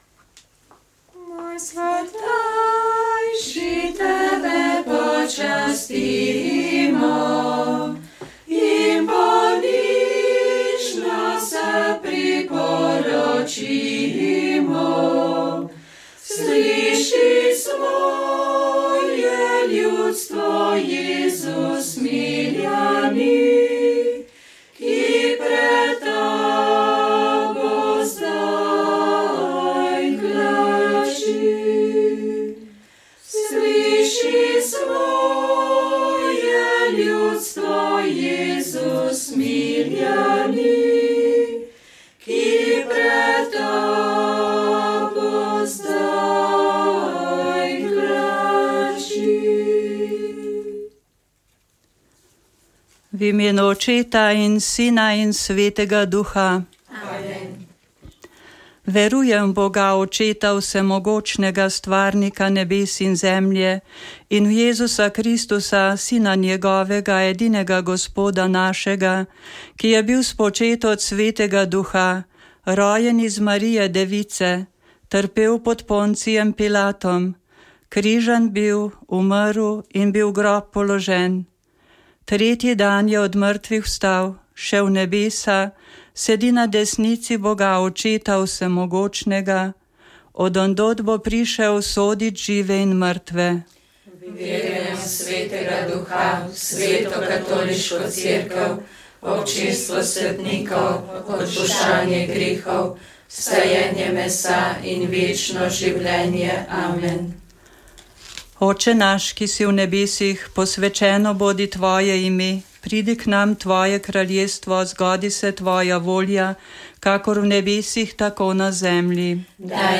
05_molitev_sestre_usmiljenke.mp3